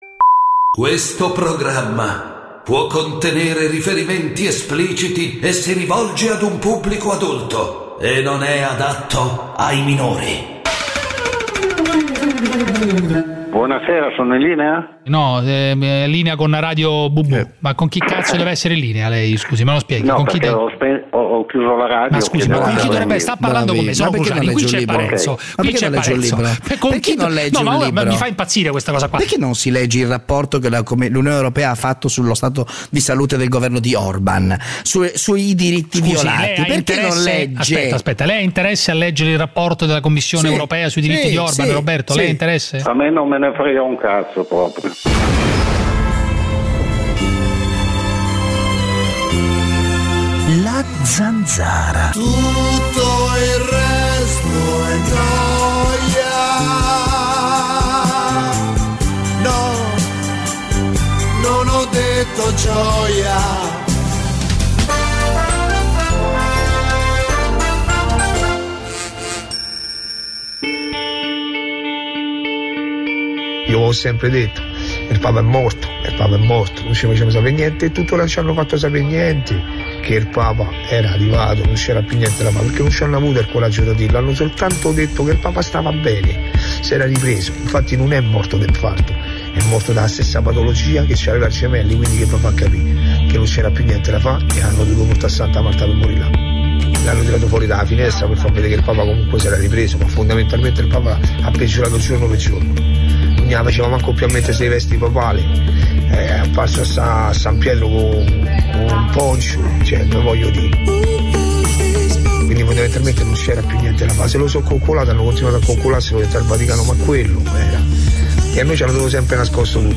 Giuseppe Cruciani con David Parenzo conduce "La Zanzara", l'attualità senza tabù, senza censure, senza tagli alle vostre opinioni. Una zona franca per gli ascoltatori, uno spazio nemico della banalità e del politicamente corretto, l'arena dove il primo comandamento è parlare chiaro.
… continue reading 1958 jaksoa # Italia Attualità # News Talk # Notizie # Radio 24